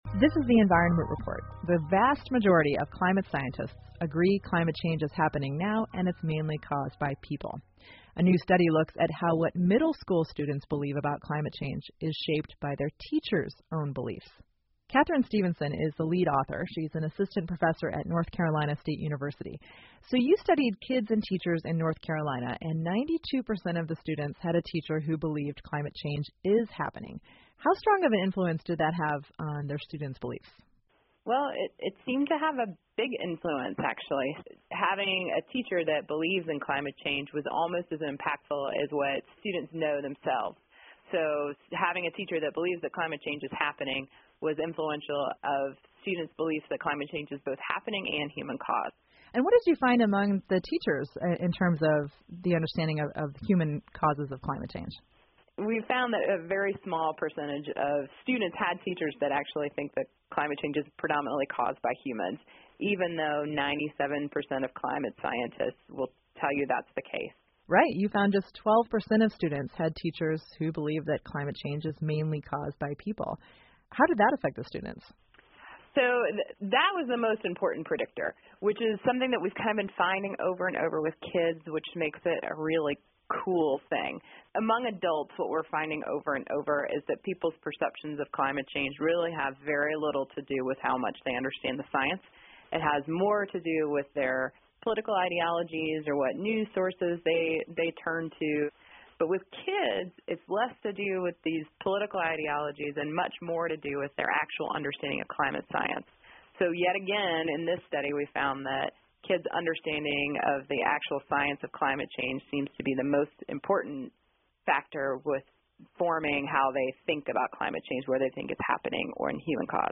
密歇根新闻广播 老师们是怎么看待全球变暖的?对学生有什么影响?